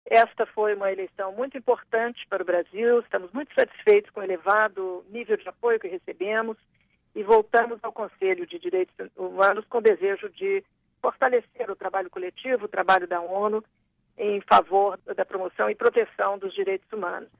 A embaixadora do Brasil nas Nações Unidas, Maria Luiza Ribeiro Viotti, falou à Rádio ONU em Nova York após a votação: